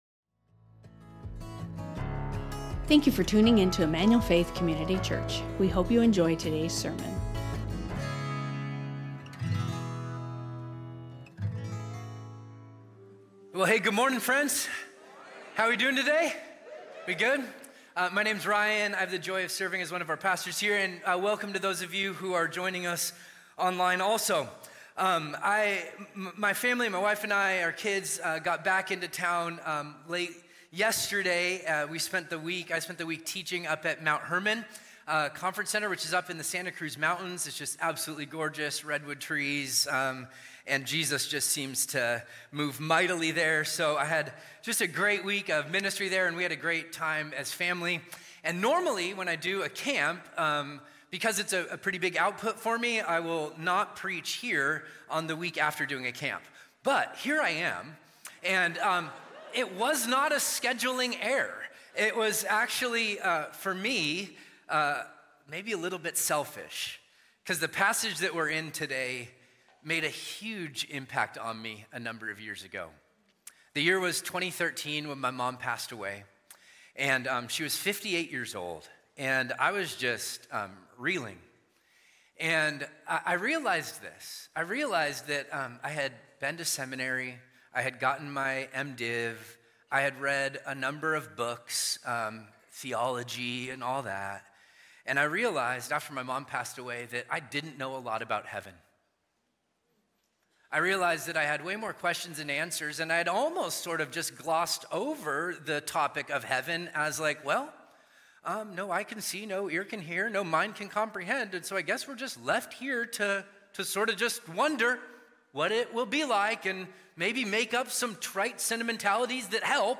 Emmanuel Faith Sermon Podcast In the Mean Time | Revelation 6:1-17 Aug 04 2025 | 00:47:45 1x 00:00 / 00:47:45 Subscribe Share Spotify Amazon Music RSS Feed Share Link Embed